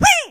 Media:mrp_minip_atk_01.ogg Mr. P laughs
P先生的笑声